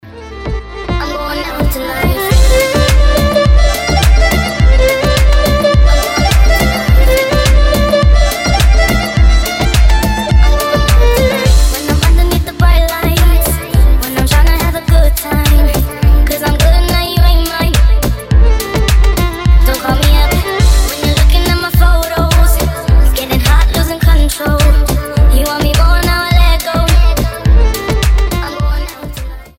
• Качество: 320, Stereo
женский вокал
remix
deep house
восточные мотивы
скрипка